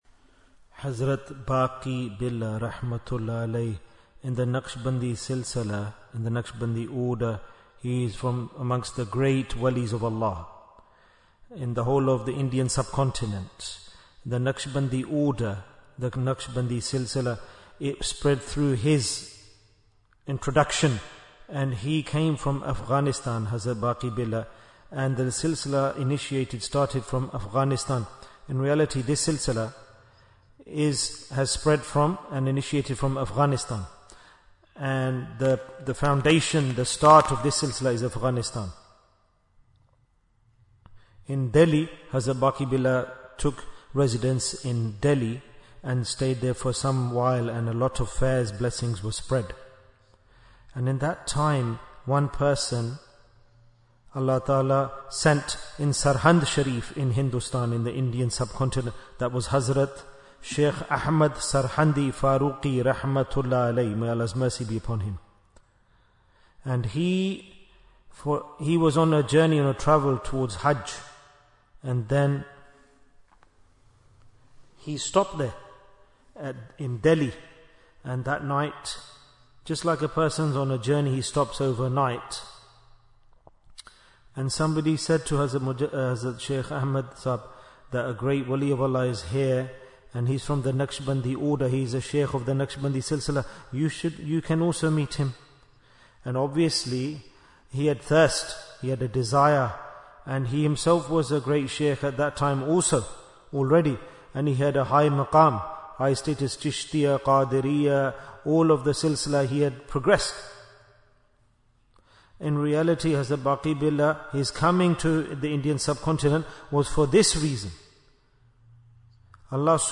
Jewels of Ramadhan 2025 - Episode 26 Bayan, 15 minutes20th March, 2025